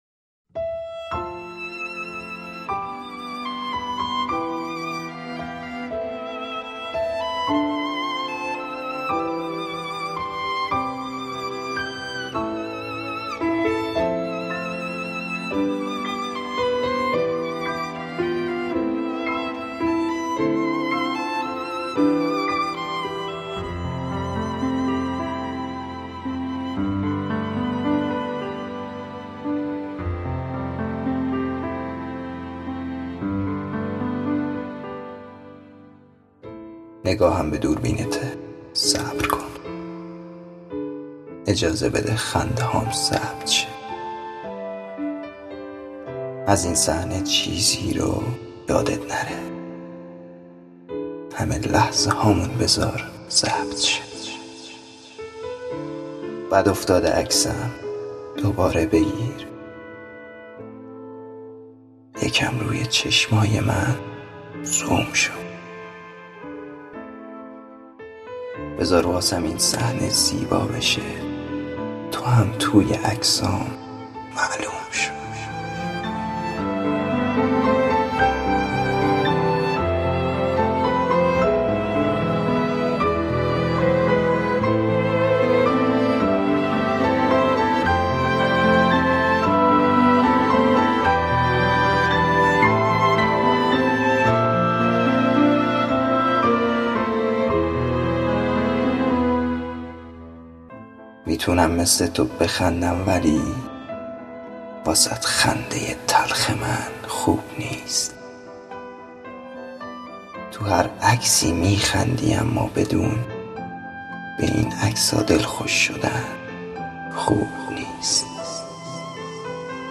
نفرات برتر چالش گروهی دکلمه دکلمافون – دوره ۶